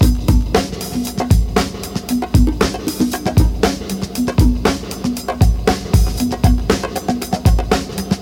an apache break.wav